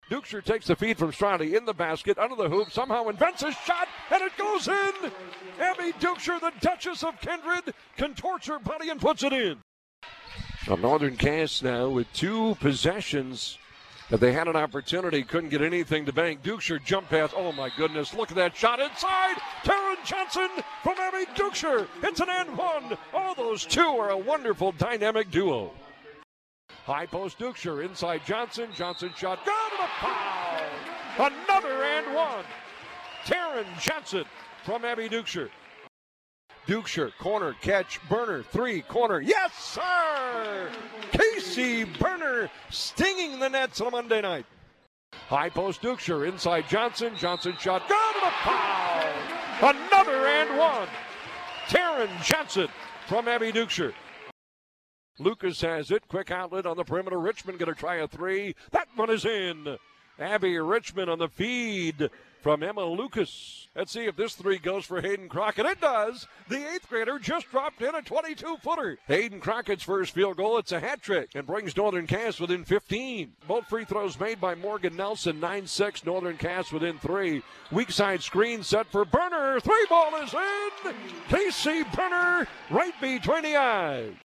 (Game Highlights)